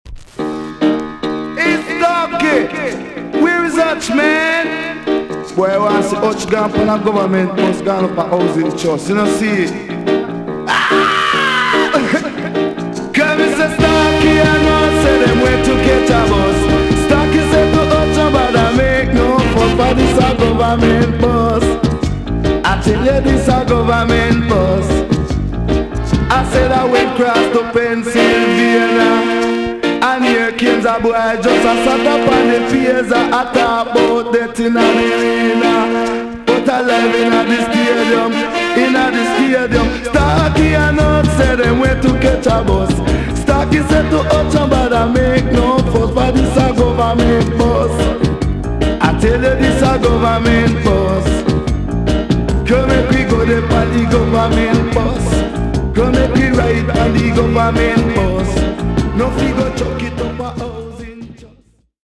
TOP >REGGAE & ROOTS
EX- 音はキレイです。
1978 , NICE TOASTING STYLE!!